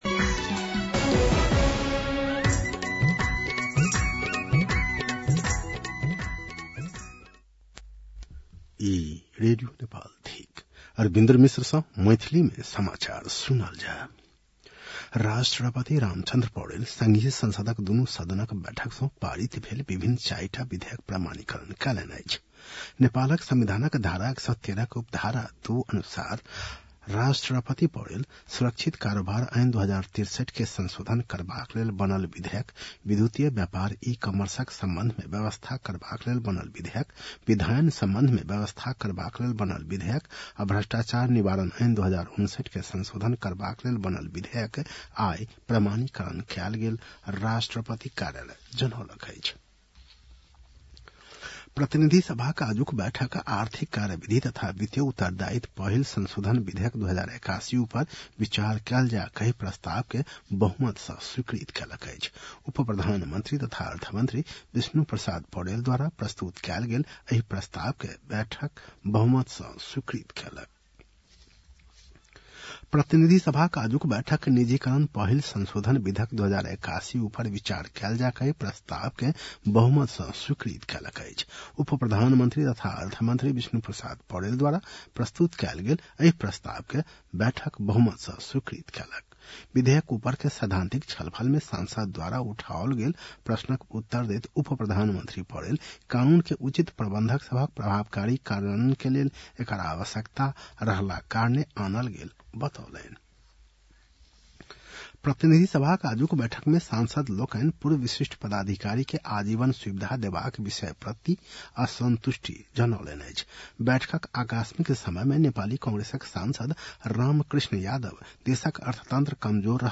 मैथिली भाषामा समाचार : ३ चैत , २०८१